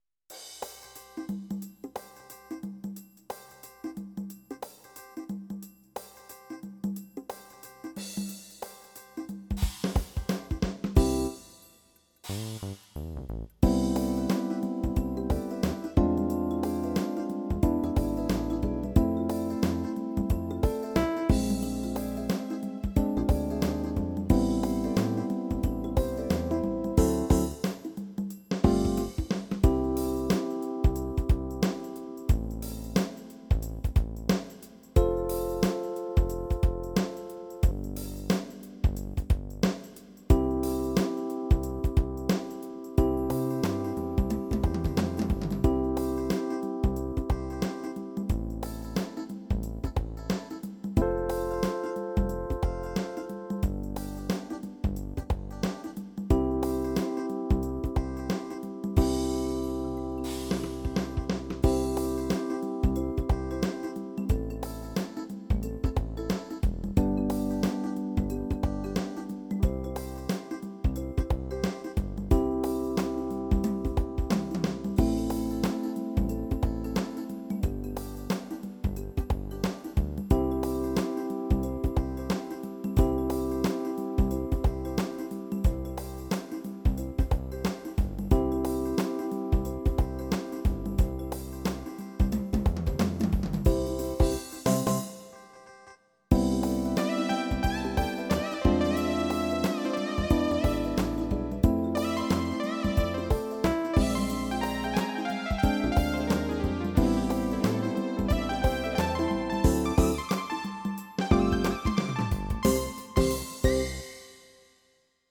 blackfunk